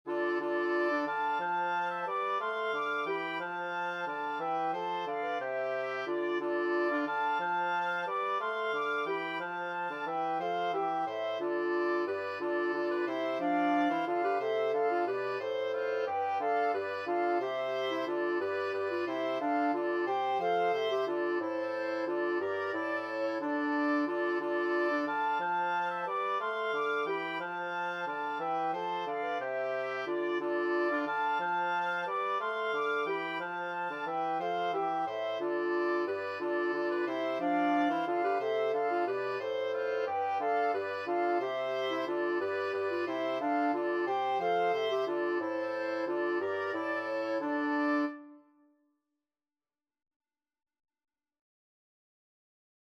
Free Sheet music for Wind Quartet
A minor (Sounding Pitch) (View more A minor Music for Wind Quartet )
6/4 (View more 6/4 Music)
Wind Quartet  (View more Easy Wind Quartet Music)
Classical (View more Classical Wind Quartet Music)
danserye_25_hoboeken_WQT.mp3